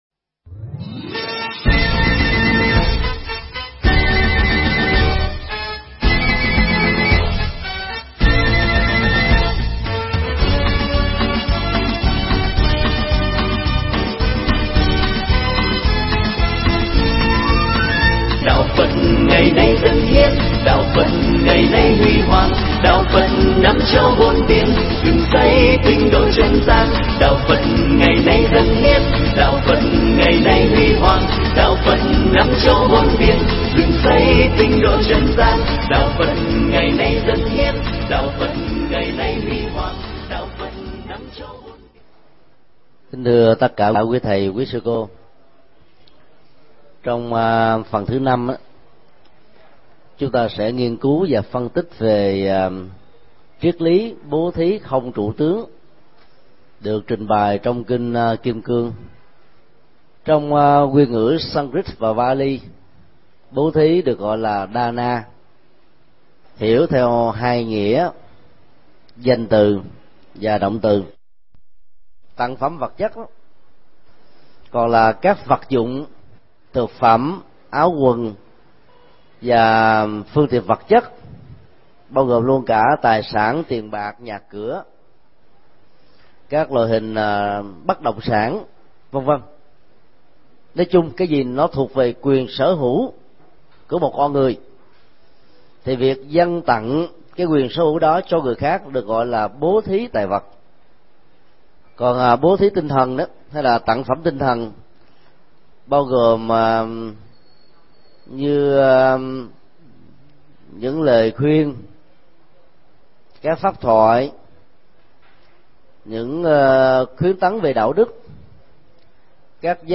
Mp3 Thuyết pháp Kinh Kim Cang 5: Bố Thí Không Trụ Tướng
giảng tại học viện Phật Giáo VN TP.HCM